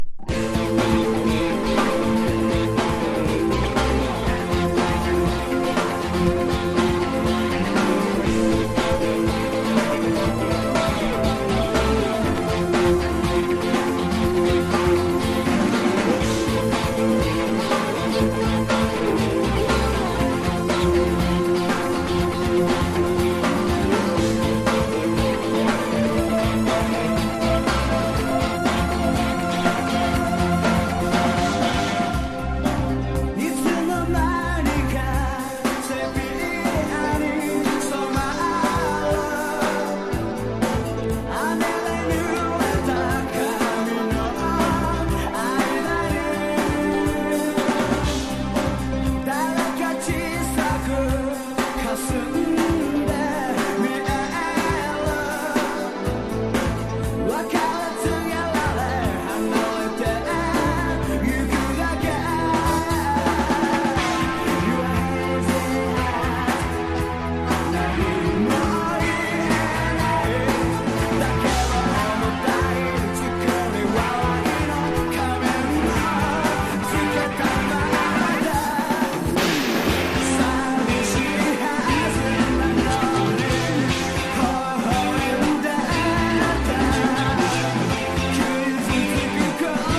60-80’S ROCK